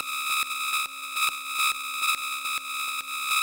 描述：一个奇怪的上升器的声音，用频率调制合成器制作。在很多电子流派中都有很好的表现，如techno、electro、glitch、dubstep等等。
Tag: 140 bpm Electronic Loops Synth Loops 590.67 KB wav Key : A